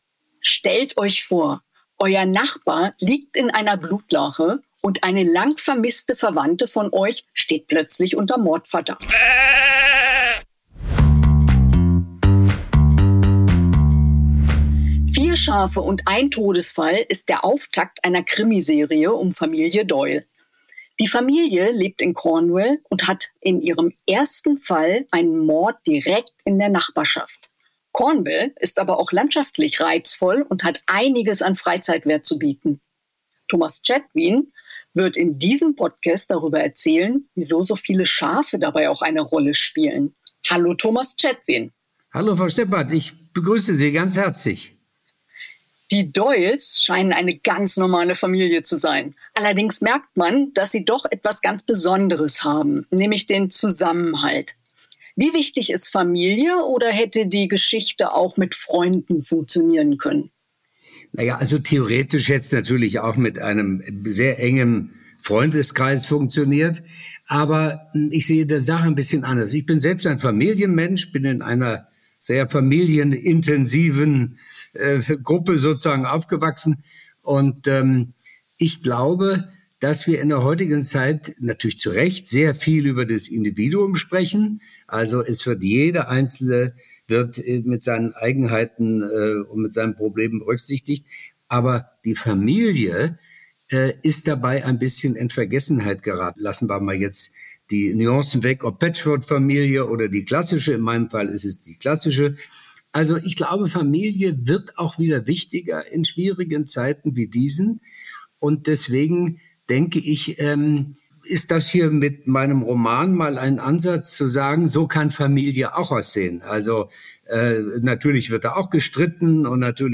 Der Englandkenner verrät im Interview ein bisschen über Cornwall, über Familie und was als Nächstes kommt.